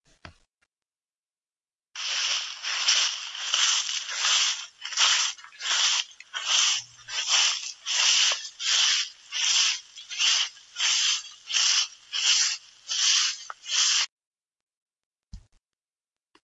丛林漫步的声音
描述：基本上是一个人走路的配乐。包含草沙沙，叶子和树叶被推到一边，棍棒在脚下嘎吱作响等噪音。偶尔会对麦克风中的风抱怨。 :(
Tag: 现场录音 丛林行走 叶子 森林 灌木 树木 自然 树叶 紧缩 沙沙 沙沙声 树枝 灌木 灌木